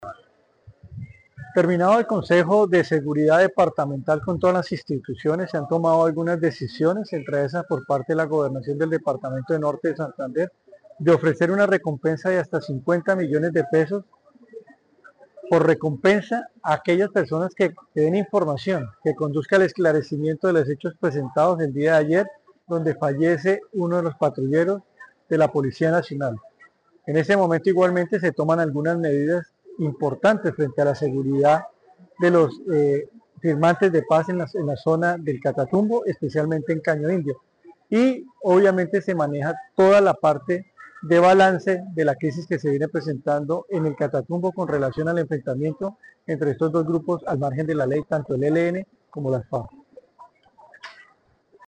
1. Audio de George Quintero, secretario de Seguridad Ciudadana
Audio-de-George-Quintero-secretario-de-Seguridad-Ciudadana.mp3